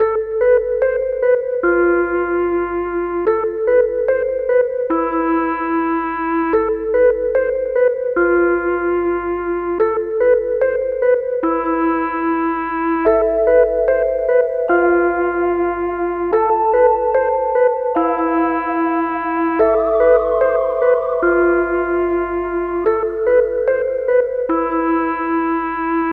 SOUTHSIDE_melody_loop_counting_147_Am.wav